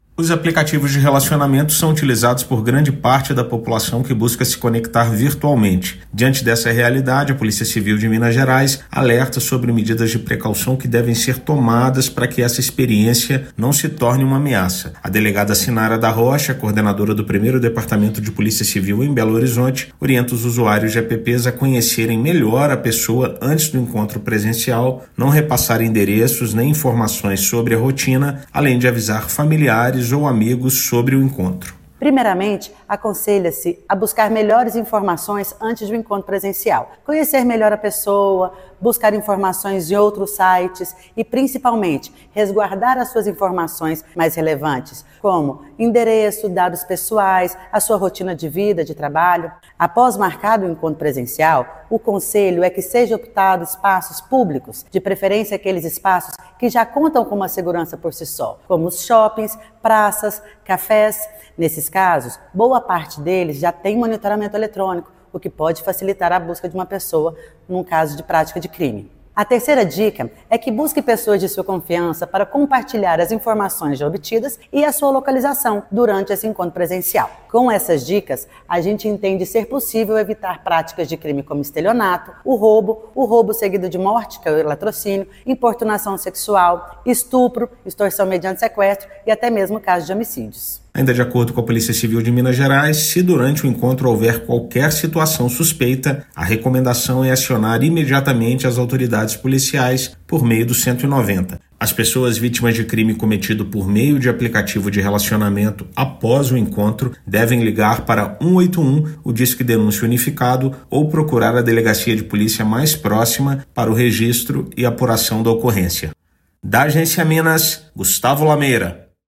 Orientações podem prevenir situações desagradáveis ou até mesmo crimes. Ouça matéria de rádio.